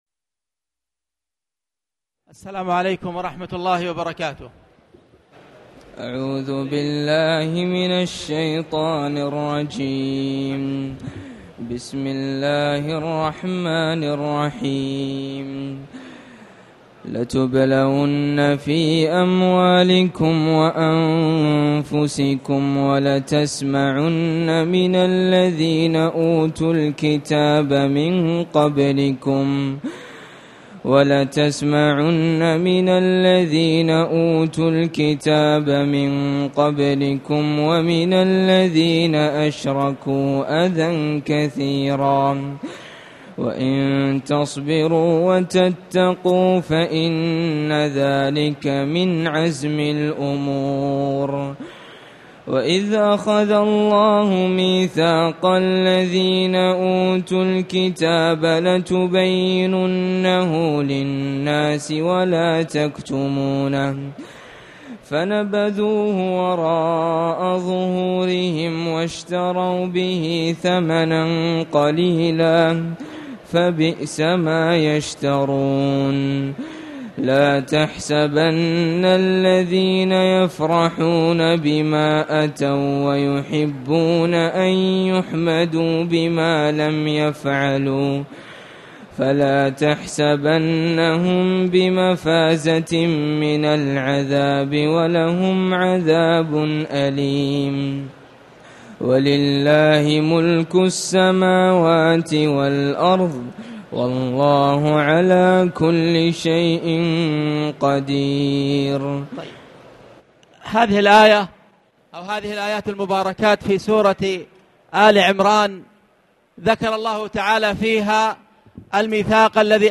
تاريخ النشر ٢٢ رمضان ١٤٣٨ هـ المكان: المسجد الحرام الشيخ